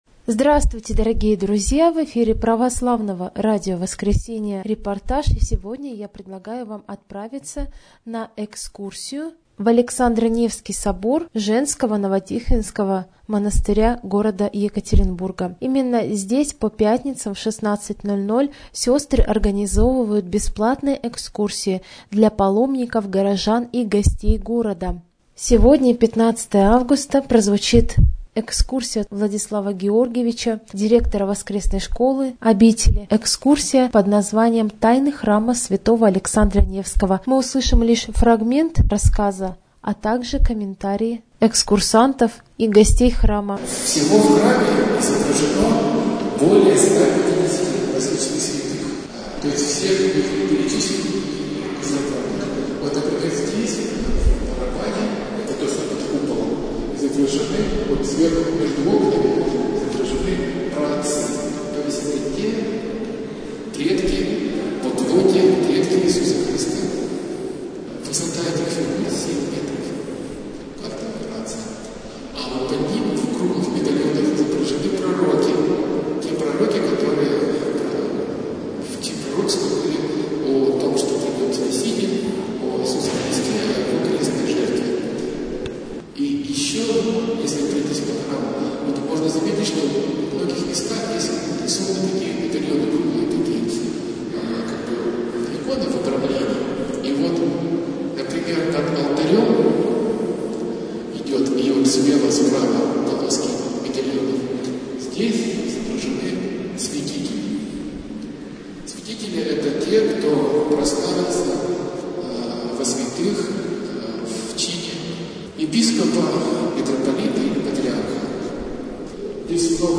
ekskursiya_v_hram_svyatogo_aleksandra_nevskogo_novo_tihvinskoj_obiteli.mp3